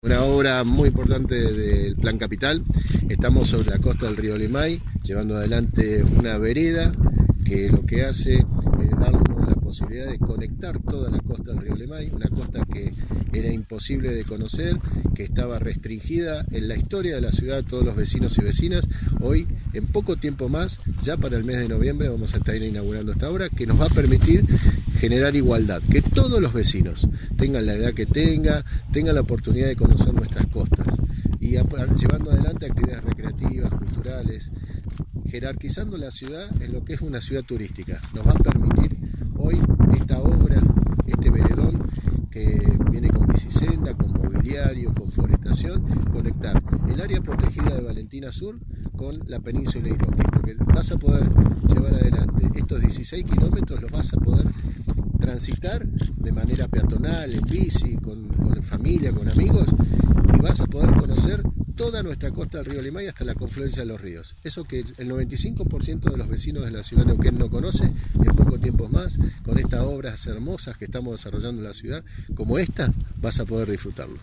Mariano Gaido, Intendente.